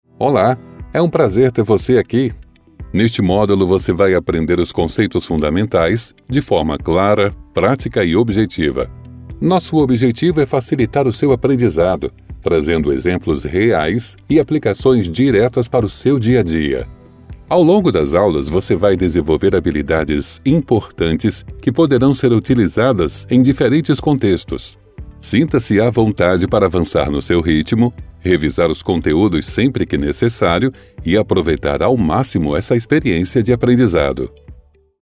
E-learning
Minha voz é humana, madura, de meia idade, respiro, cometo erros, não sou perfeccionista, porém, gosto das coisas bem feitas e estou sempre disposto a ouvir e aprender.
- Interface Scarlett 2i2 3ª geração
- Microfone austríaco AKG C 214
- Cabine com excelente acústica, silenciosa